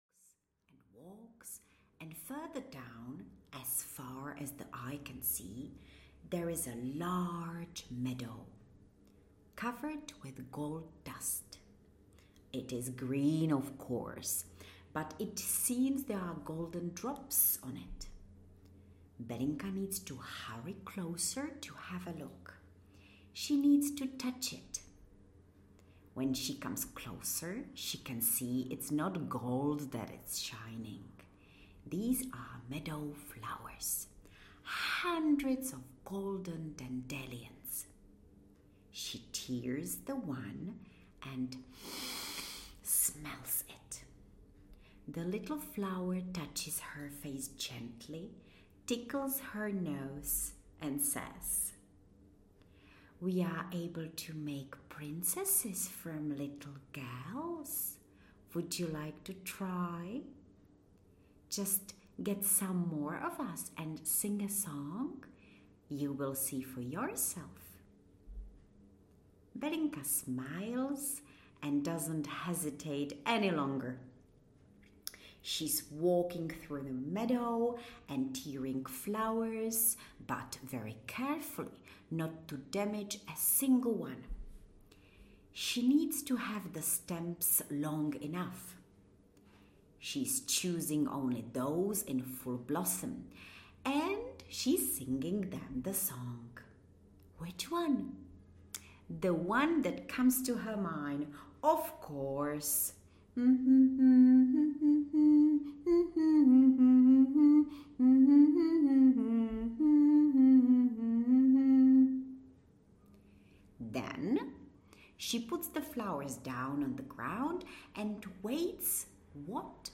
Bellinka’s trips audiokniha
Ukázka z knihy